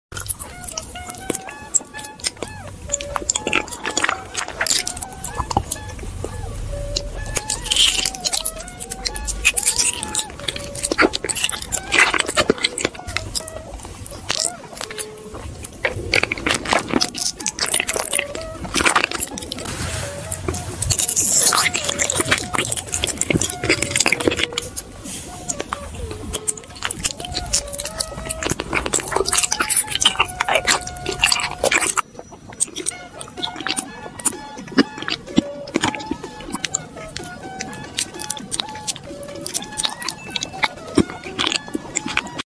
[ASMR] Makan sesuai emoji